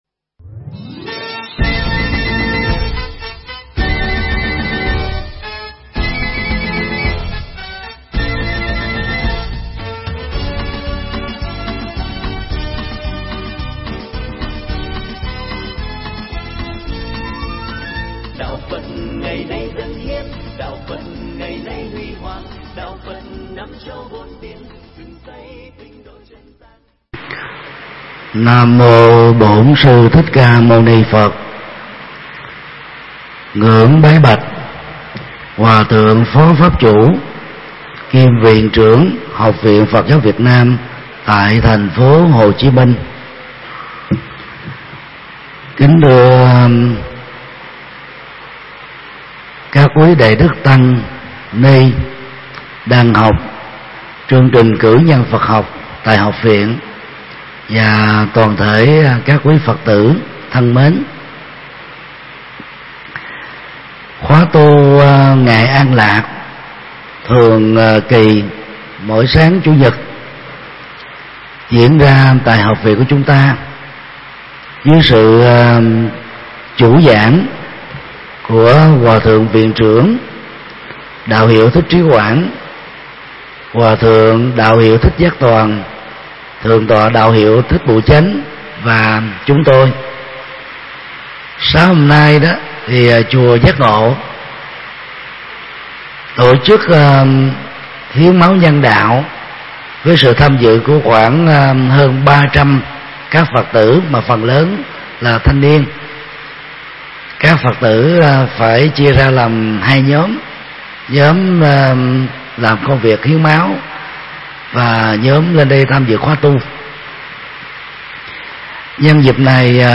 Mp3 Pháp Thoại Chấp ngã cá nhân, chấp ngã gia đình và chấp ngã quốc gia – Thượng Tọa Thích Nhật Từ giảng tại Học viện Phật giáo Việt Nam tại TP. HCM, cơ sở Lê Minh Xuân, ngày 26 tháng 6 năm 2016